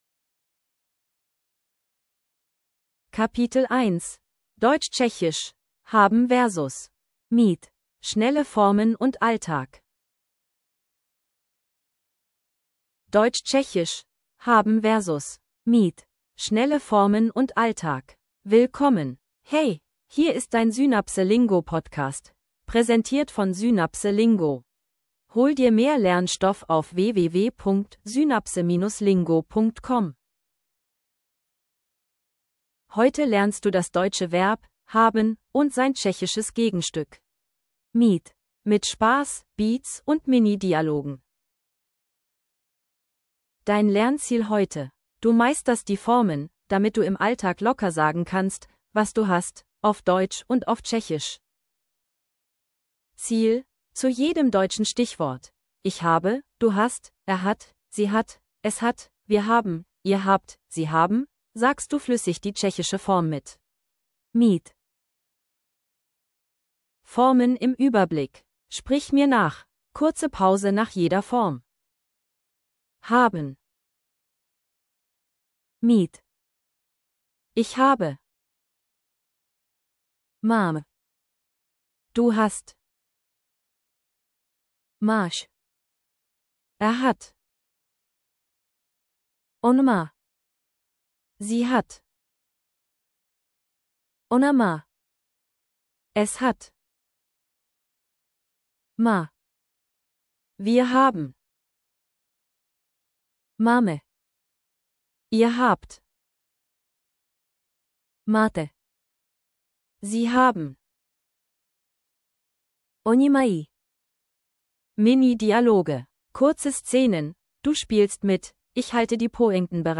Audio zum Mitsprechen & Wiederholen